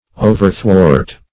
Overthwart \O"ver*thwart"\ ([=o]"v[~e]r*thw[add]rt"), a.